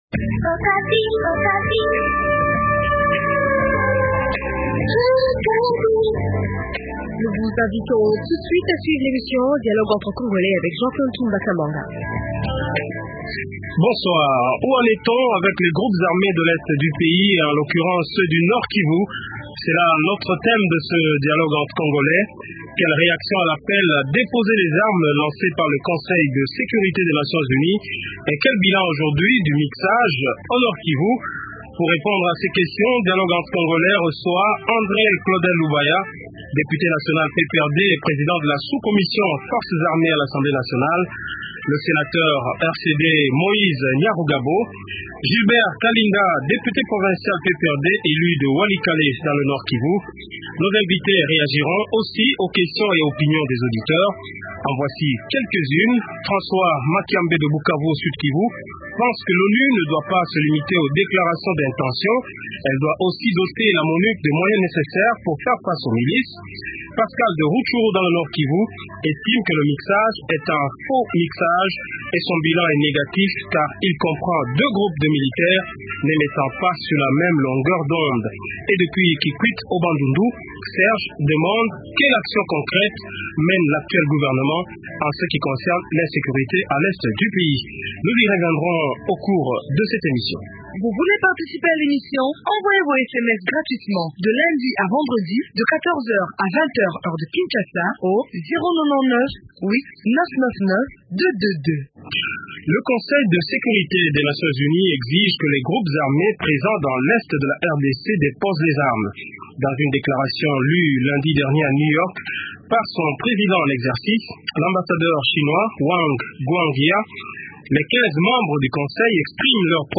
Les 15 membres du conseil de sécurité ont exprimé leur profonde préoccupation face à la détérioration de la situation sécuritaire dans cette région. Invités : - André- Claudel Lubaya, député PPRD et président de la sous commission Forces armées a l’assemblée nationale - Jean-Bosco Sebishimbo, député RCD du Nord Kivu/territoire de Masisi - Gilbert Kalinda, député provincial PPRD, élu de Walikale dans le nord Kivu rn